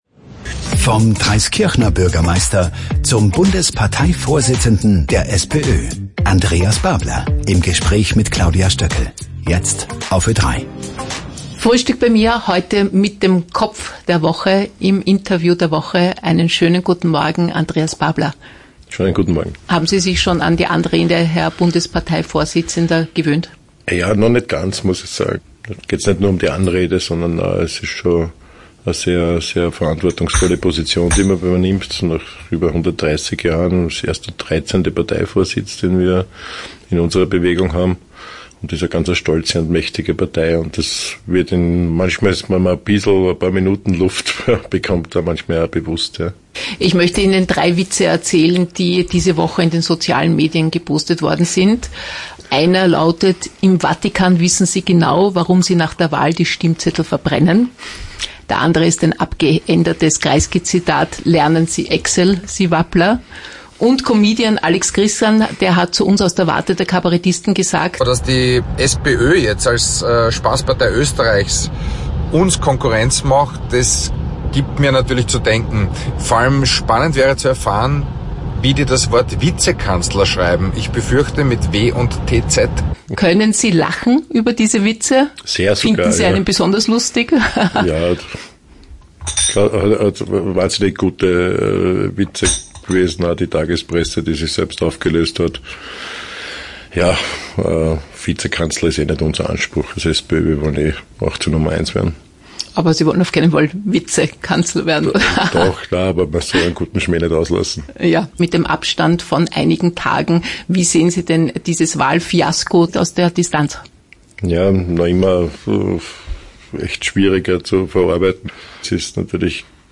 SPÖ-Chef Andreas Babler in „Frühstück bei mir“ (11. Juni 2023)